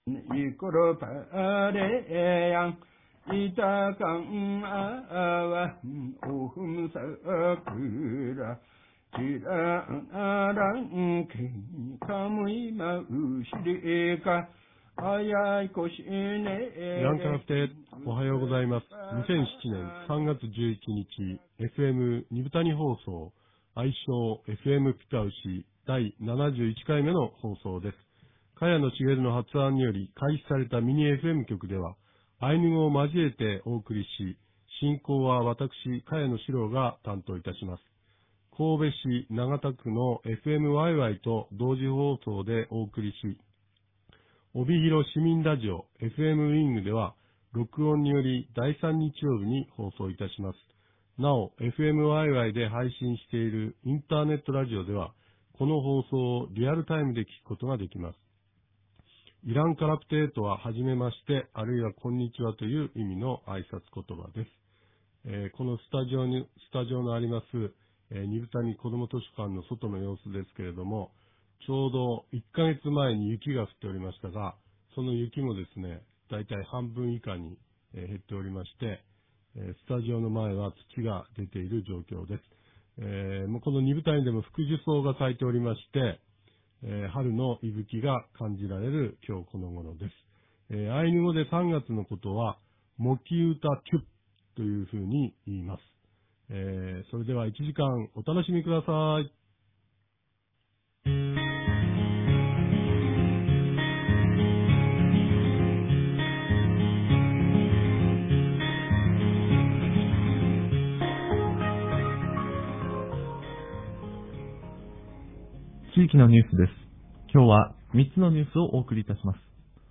■地域のニュース